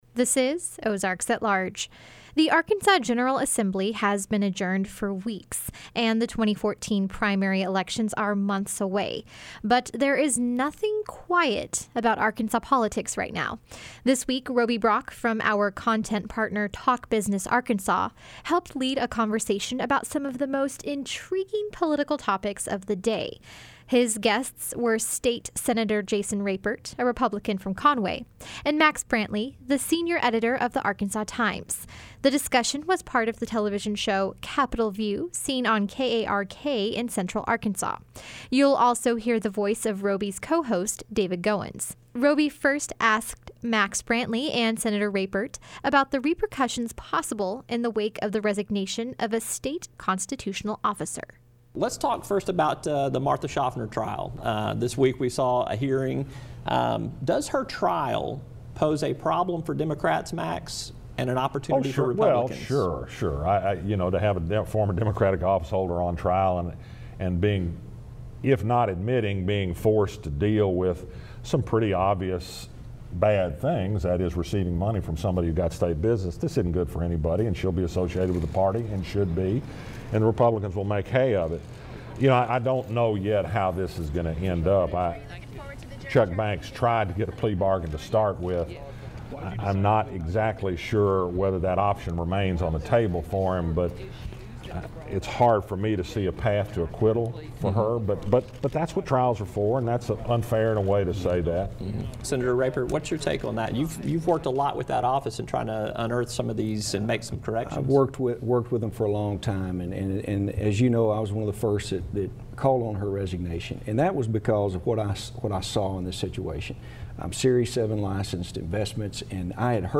The conversation comes from the program Capitol View, first broadcast in Little Rock on KARK TV.